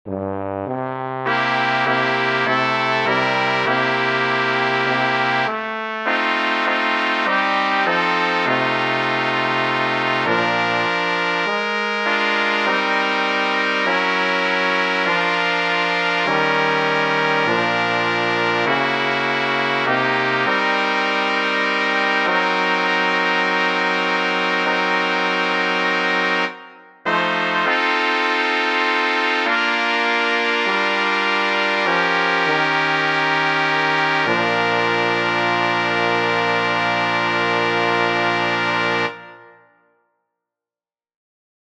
Key written in: B♭ Major
How many parts: 4
Type: Barbershop
All Parts mix: